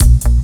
DUBLOOP 04-R.wav